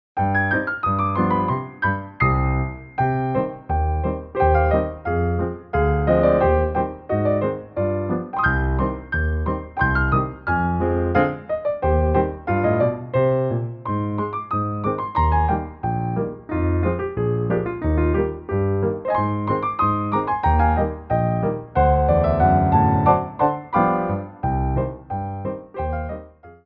2/4 (16x8)